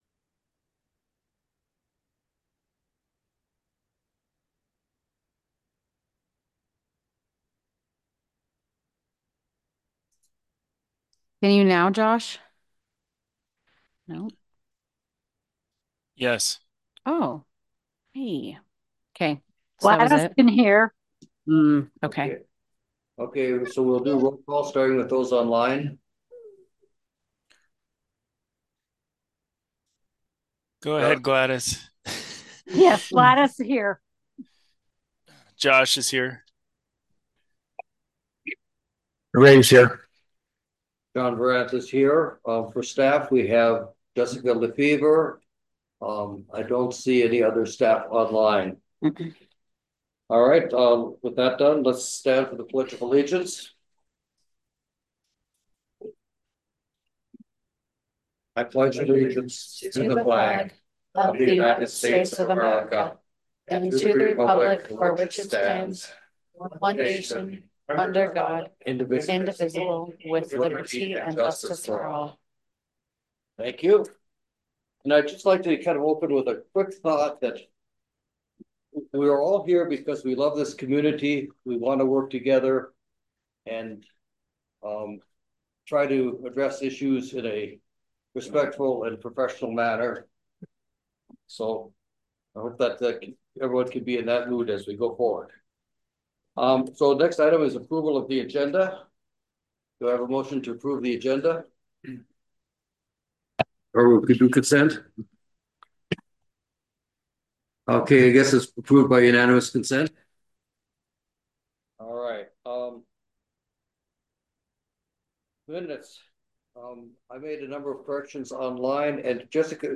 The Boulder Town Council will hold its regular meeting on Thursday April 03, 2025, starting at 7:00 pm at the Boulder Community Center Meeting Room, 351 No 100 East, Boulder, UT.